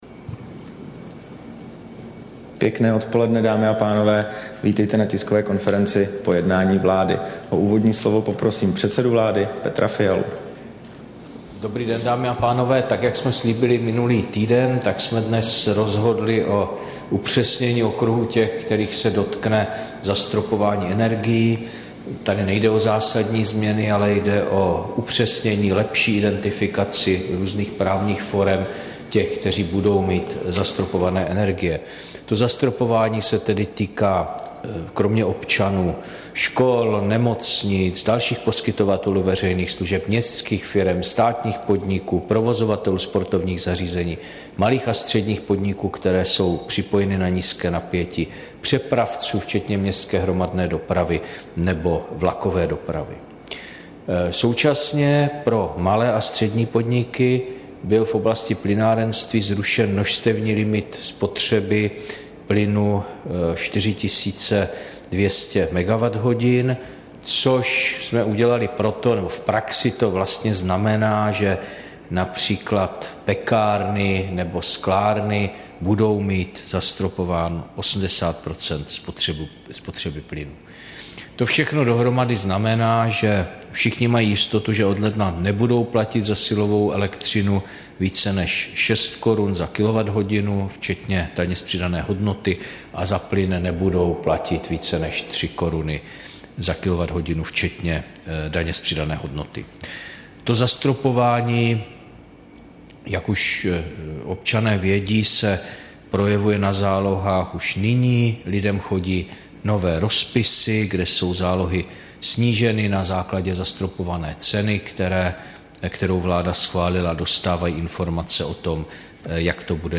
Tisková konference po jednání vlády, 2. listopadu 2022